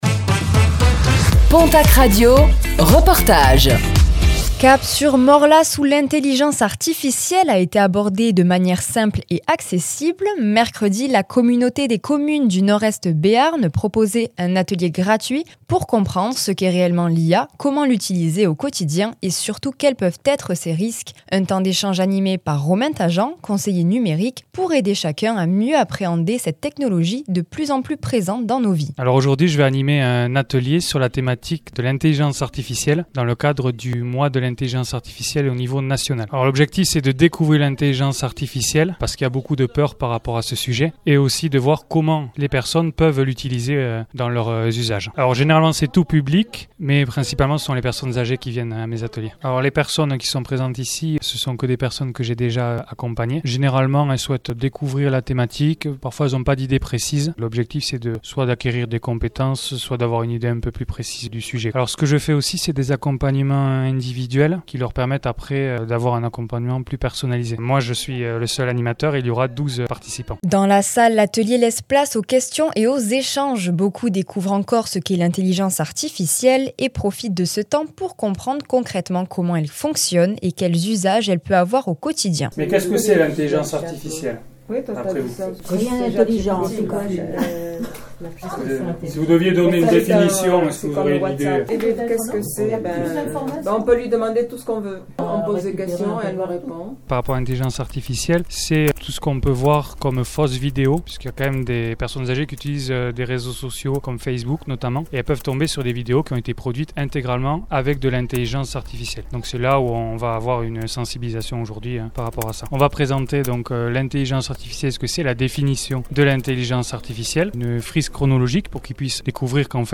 À Morlaàs, un atelier pour mieux comprendre l’intelligence artificielle - Reportage du jeudi 15 janvier 2026